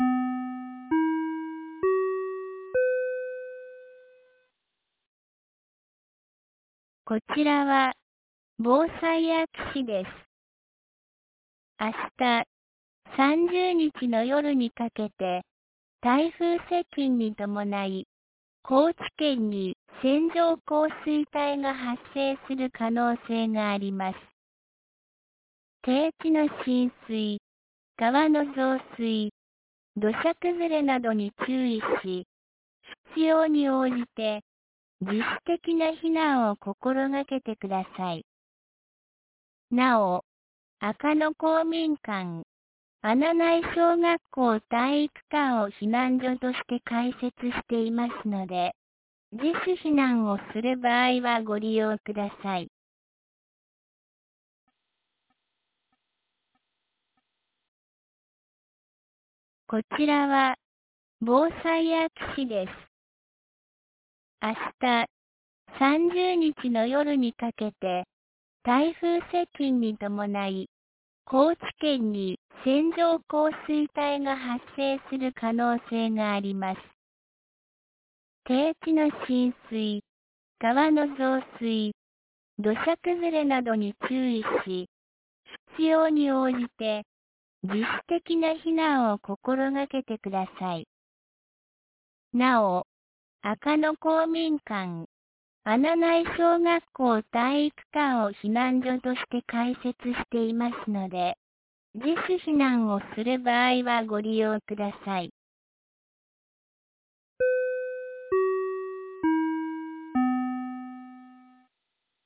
2024年08月29日 15時21分に、安芸市より穴内、赤野へ放送がありました。